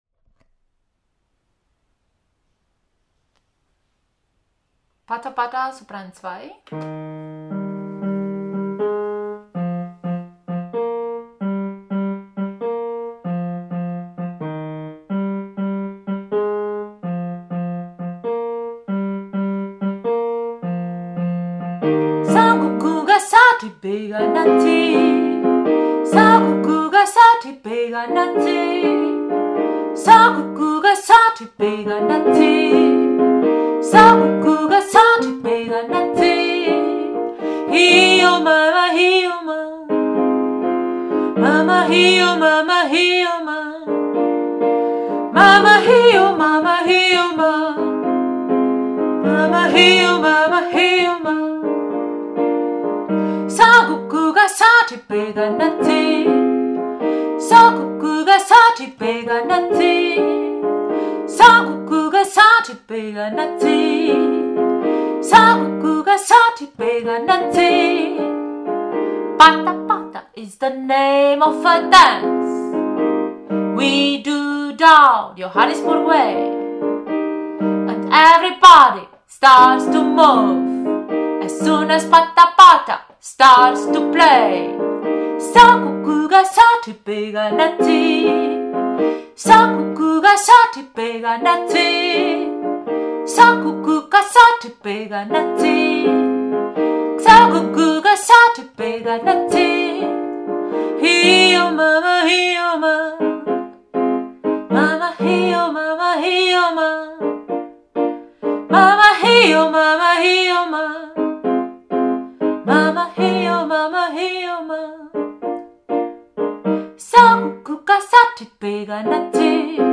Pata Pata – Sopran2/Alt1
Pata-Pata-Sopran2.mp3